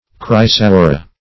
chrysaora.mp3